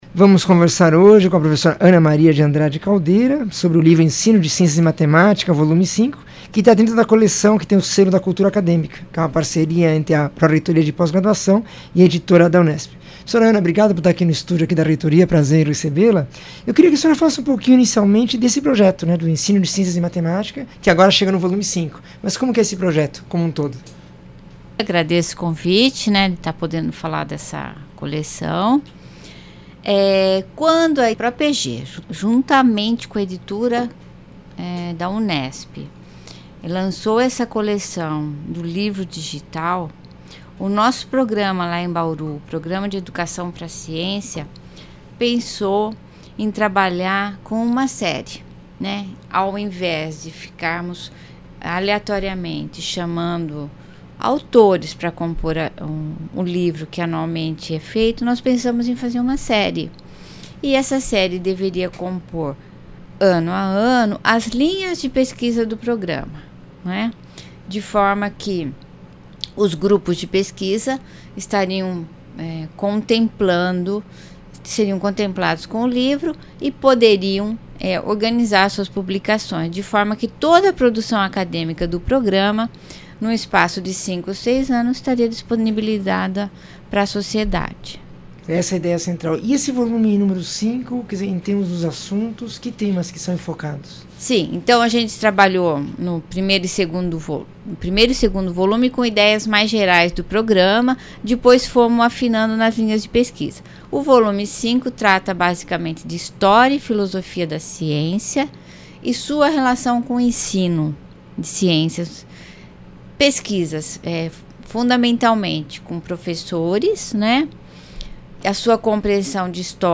entrevista 1396